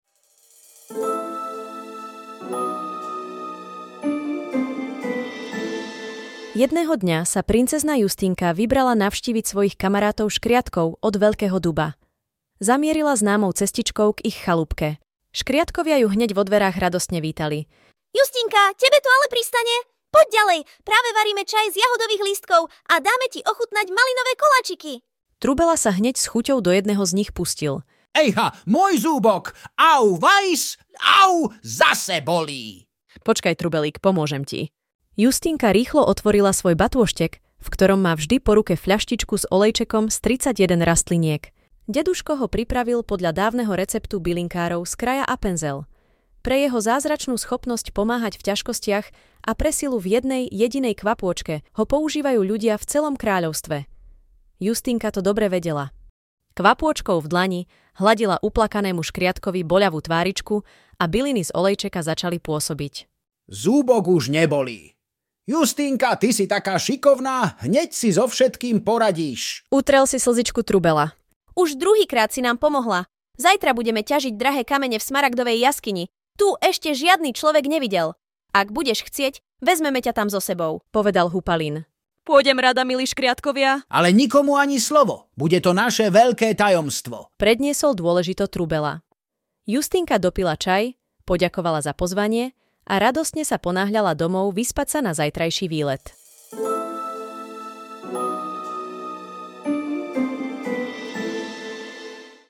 Možno vás trochu prekvapia nezvyčajné hlasy, či intonácia. Využili sme služby umelej inteligencie pre lepšiu predstavivosť, ale určite nám dáte za pravdu, že skutočný hlas mamičky, otecka či starej mamy to nenahradí.
uryvok-bylinkove-rozpravky_so-zvuckou.mp3